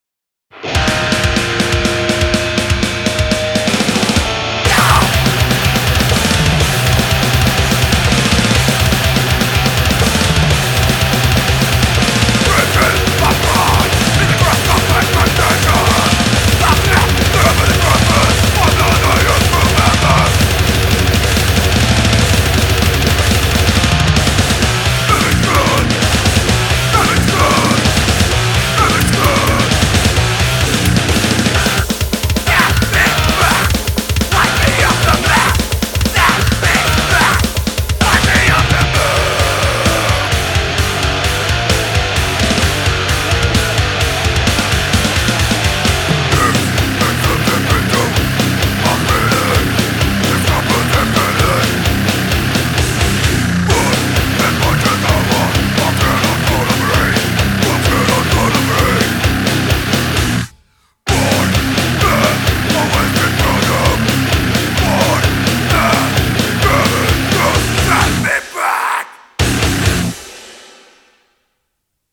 Grindcore / Thrashgrind
Just pure throat-shredding panic.